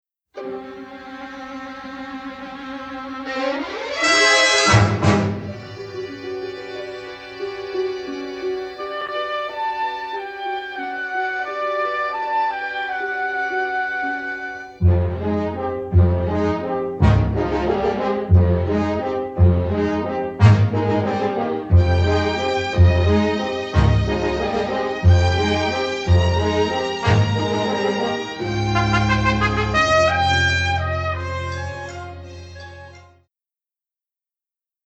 western score
complete score mastered in mono from print takes